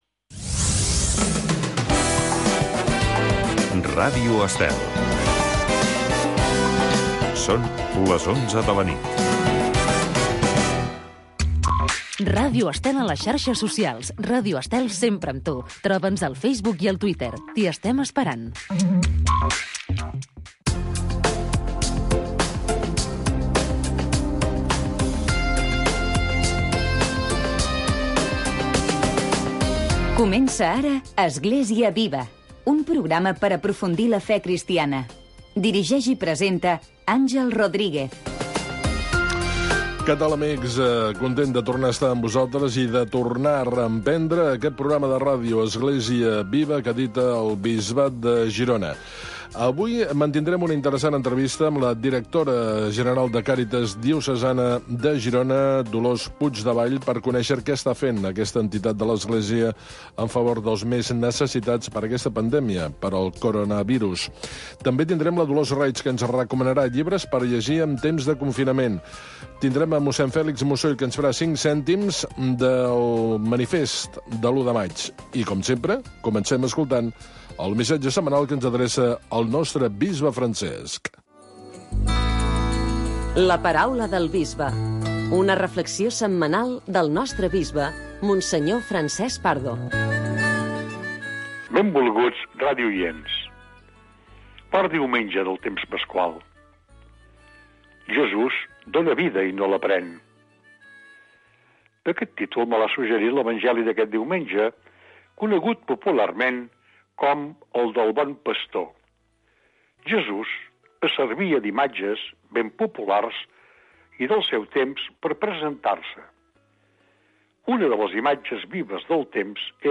Església viva. Magazine d’actualitat cristiana del bisbat de Girona.